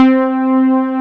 MOOG C5.wav